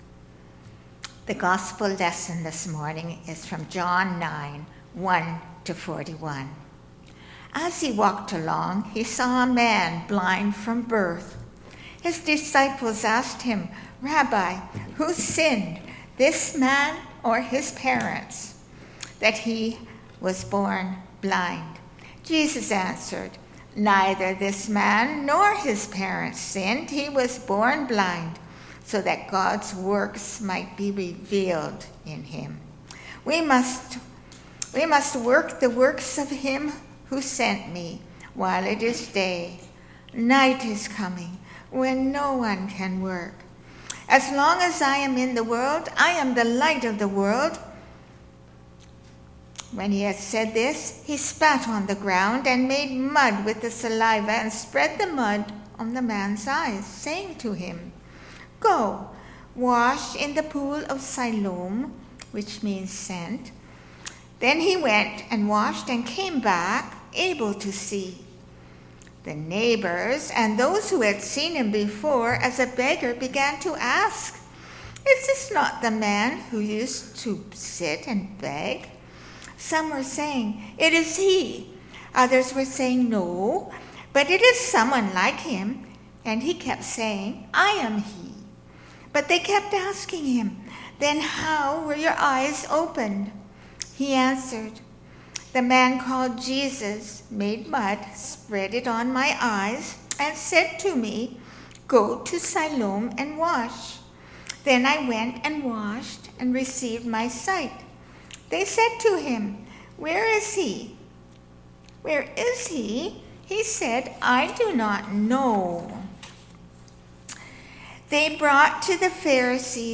This is a shortened version of our in-person  Sunday Service.